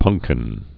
(pŭngkĭn)